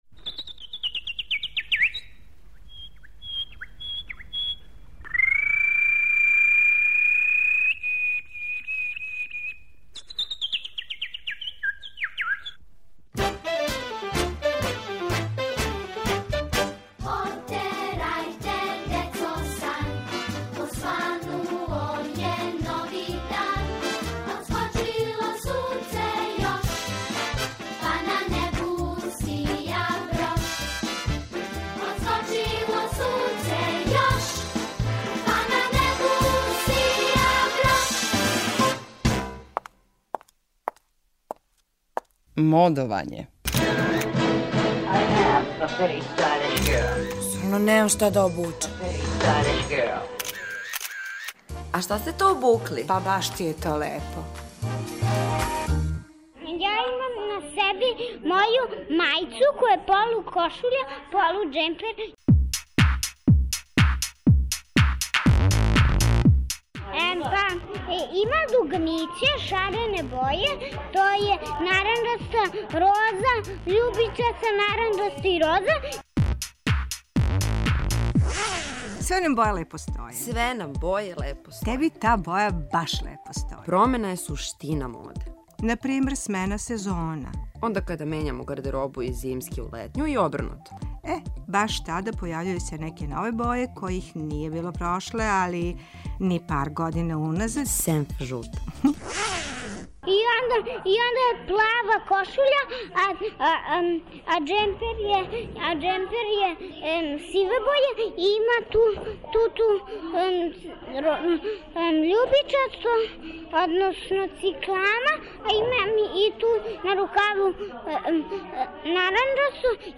У серијалу Модовање случајни, лепо одевени пролазници, описују своје одевне комбинације.